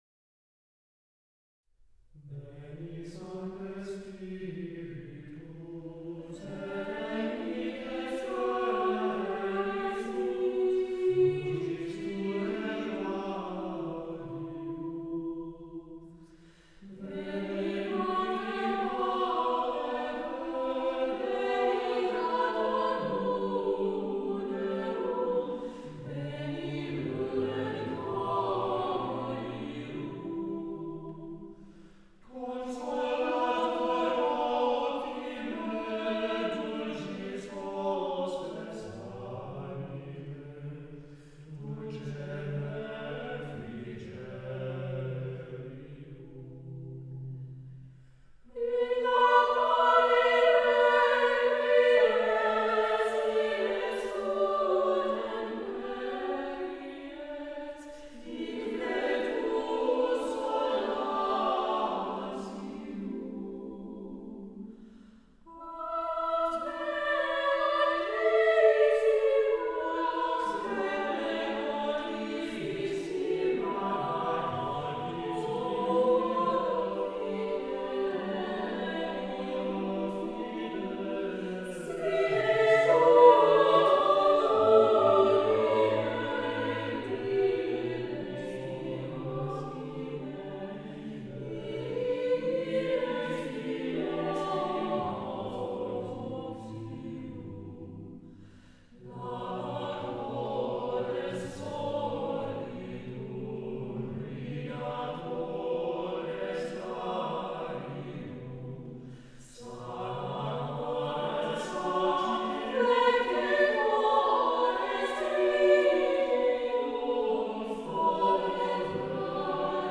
SATB a cappella (c. 3:00)